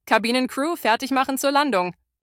CrewSeatsLanding.ogg